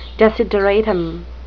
desideratum (di-sid-uh-RAY-tuhm, -RAA-) noun
X-Pronunciation: